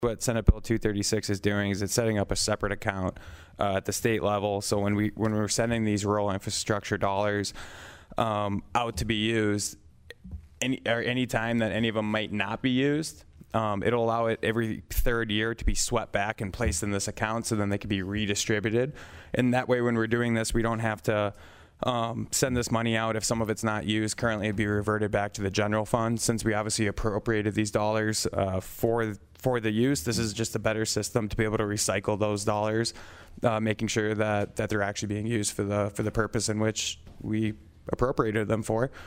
Prime sponsor on the bill was Senator Michael Rohl of Aberdeen.